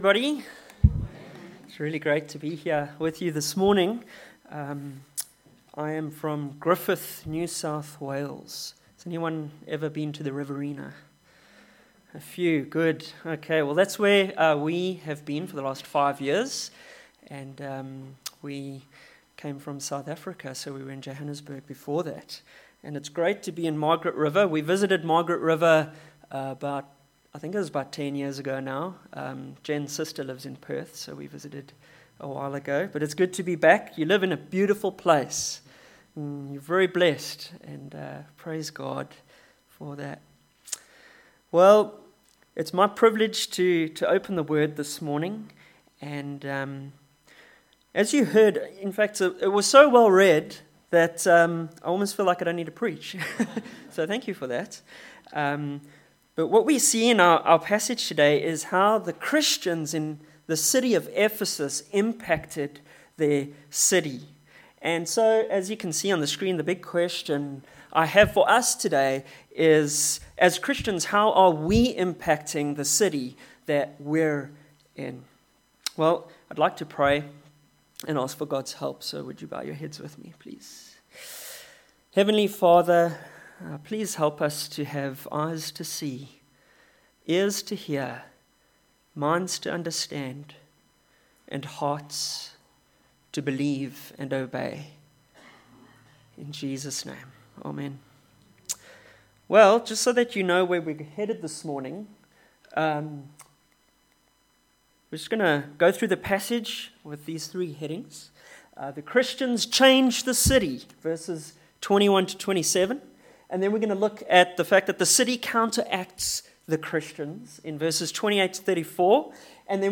21-41 Service Type: Sunday AM Bible Text